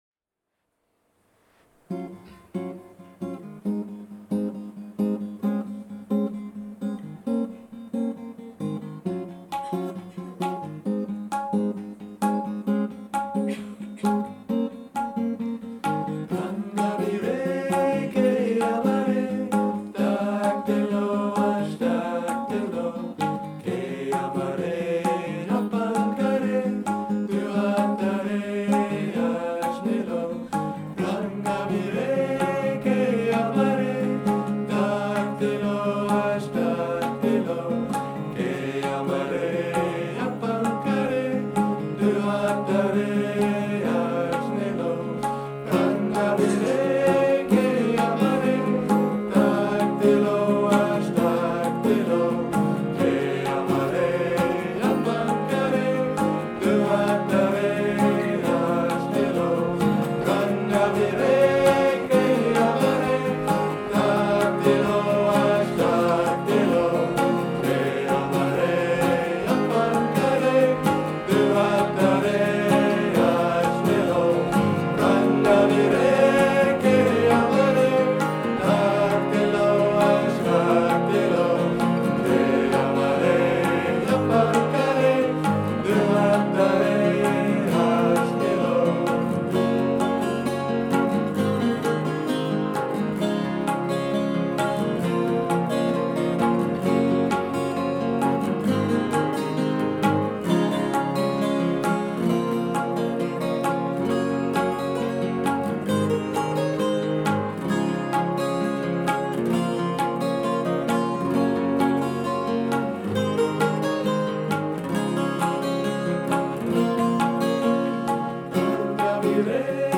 This is a selection of tracks from that concert.